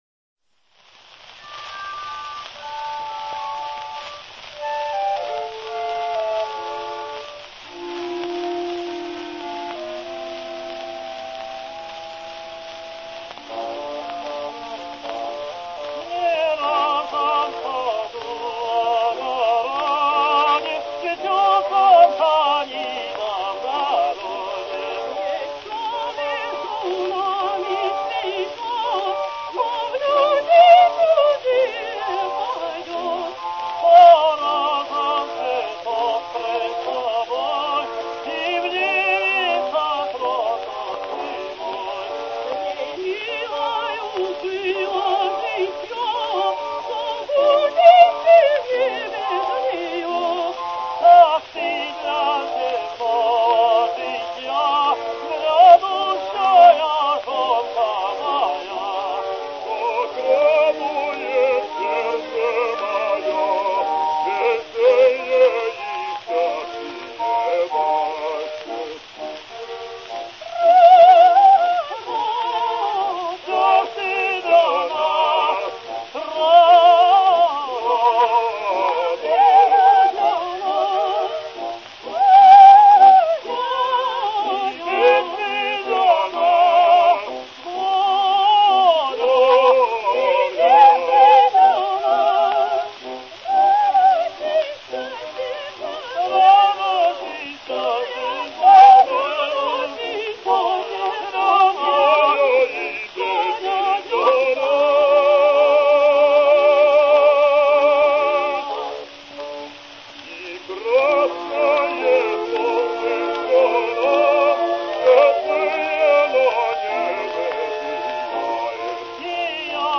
His voice had a range up to high F.
Andrej Labinskij sings Zhizn za tsarja: